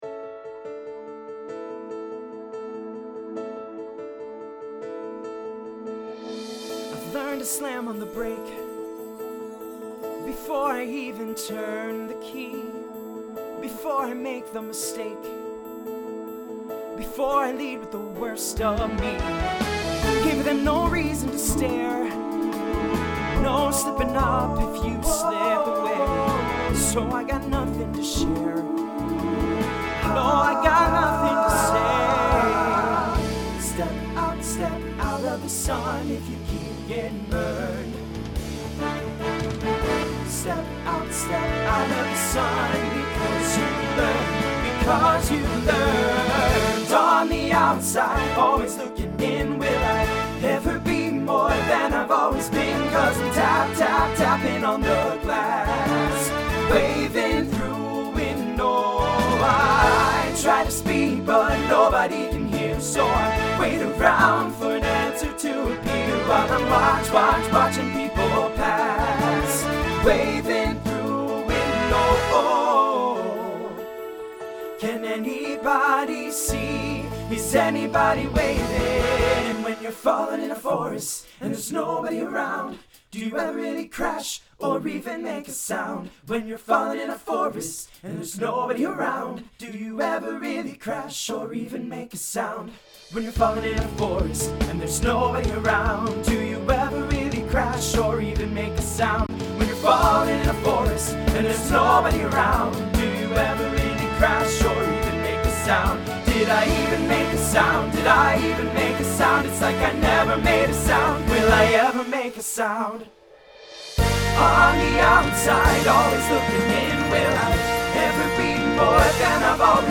Voicing TTB Instrumental combo Genre Broadway/Film